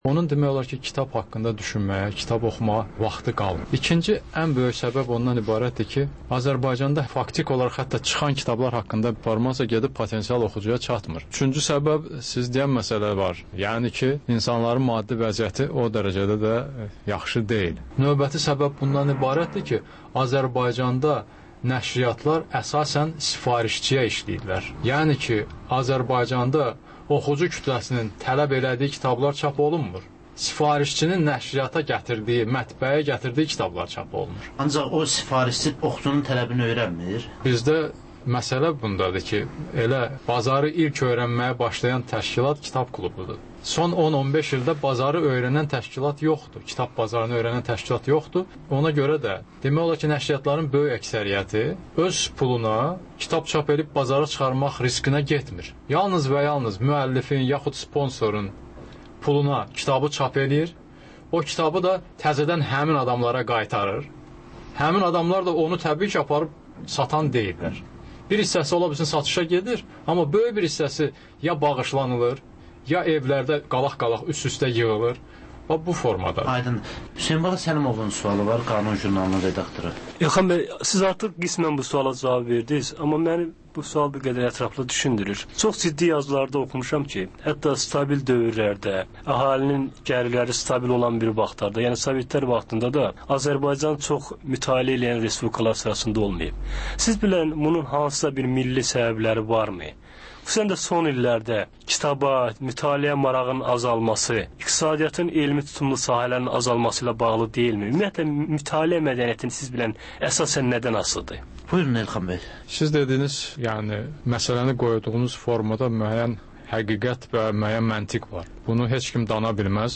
Azərbaycan, Gürcüstan ve Ermənistandan reportajlar, müzakirələr